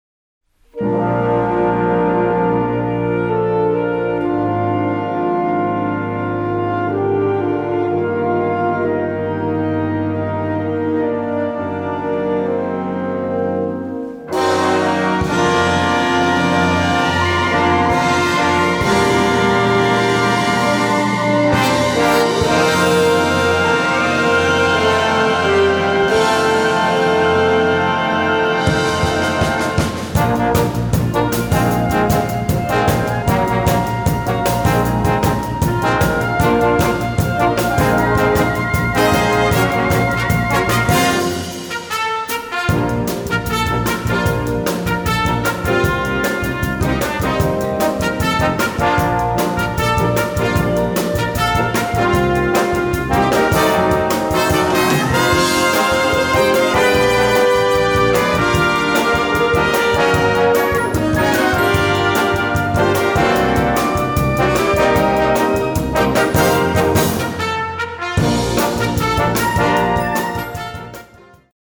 Gattung: Konzertante Unterhaltungsmusik
Besetzung: Blasorchester